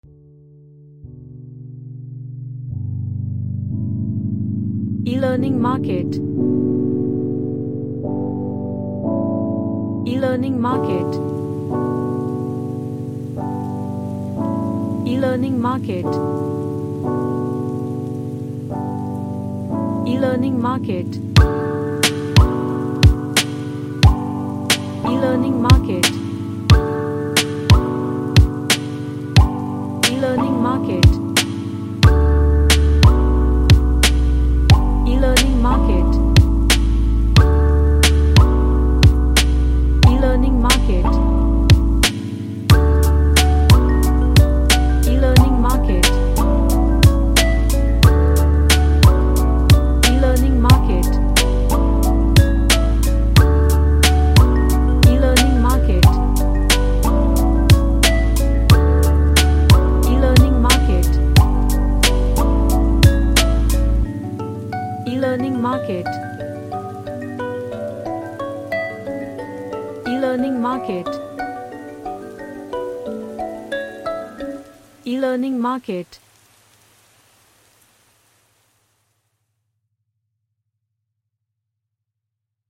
A relaxing pop track
Relaxation / MeditationAmbient